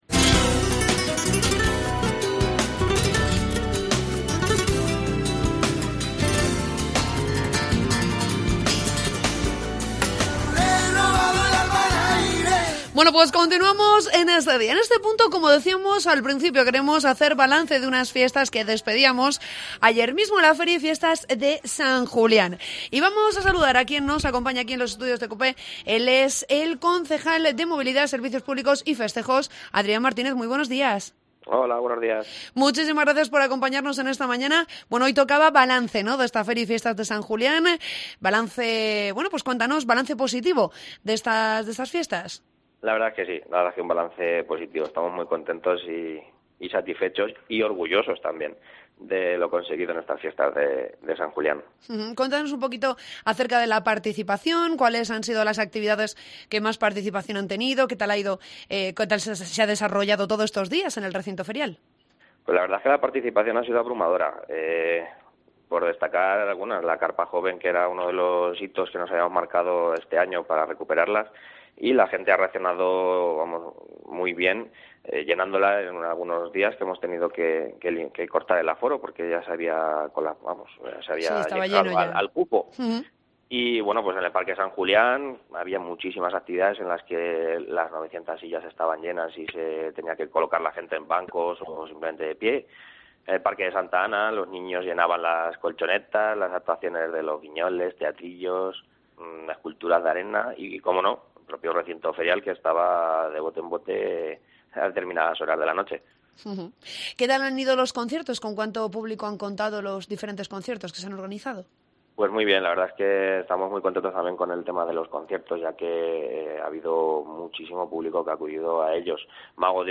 Entrevista con el concejal de Festejos, Adrián Martínez